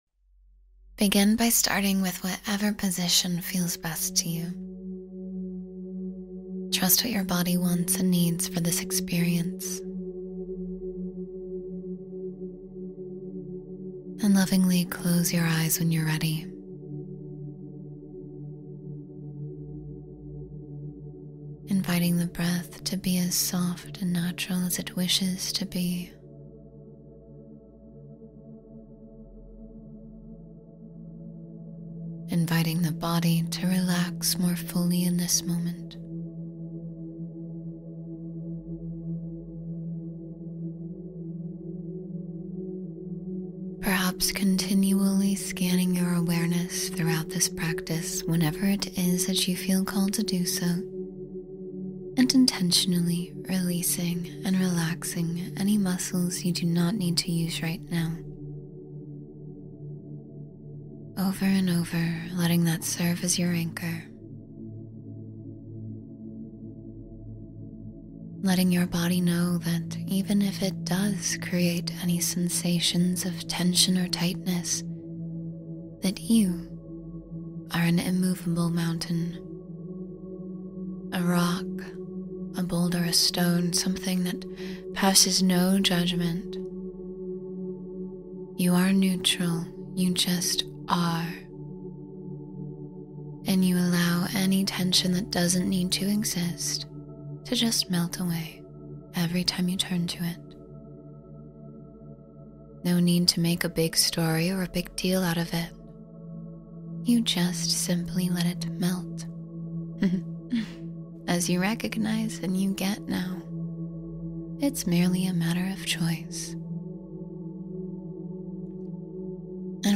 Follow the Road That Leads to Your Dreams — Meditation for Goal Fulfillment